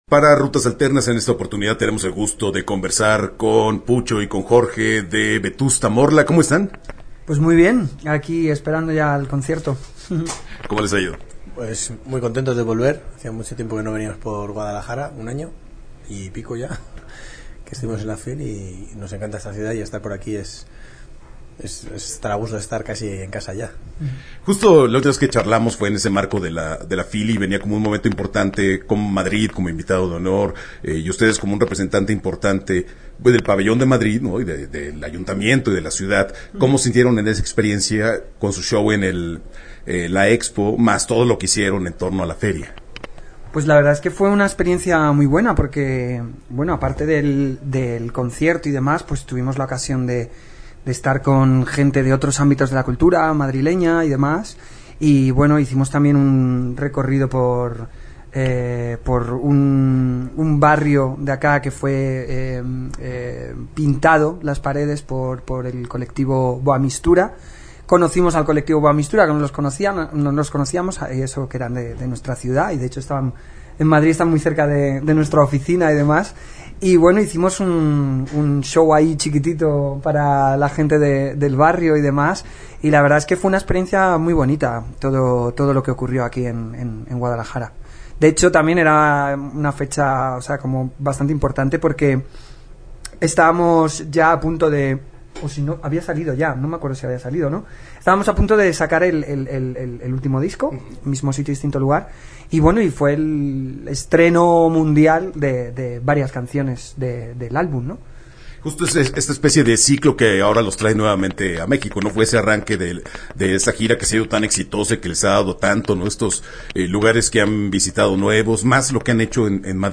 Entrevista-Vetusta-Morla-2019-web.mp3